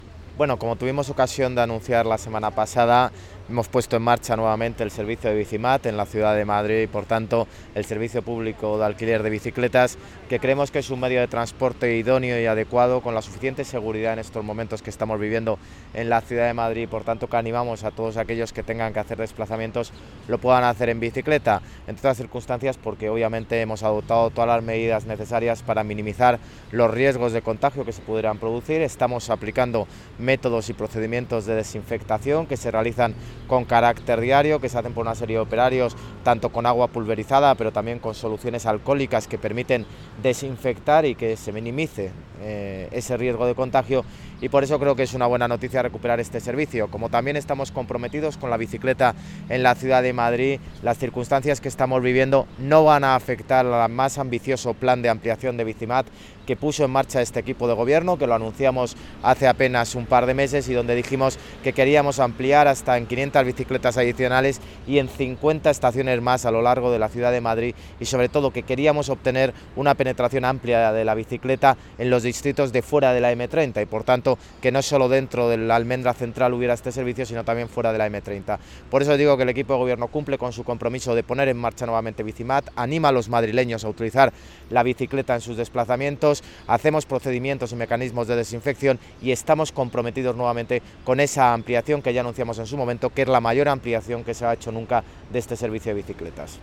En la estación de la Puerta de Alcalá, en la plaza de la Independencia
Nueva ventana:El alcalde explica las medidas adoptadas para la reapertura de BiciMad